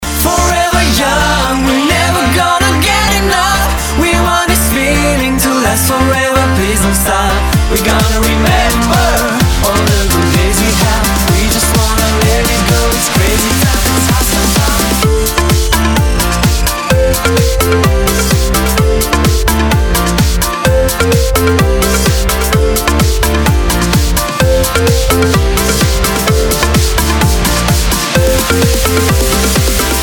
• Качество: 320, Stereo
Заводной рингтончик про бесконечную молодость!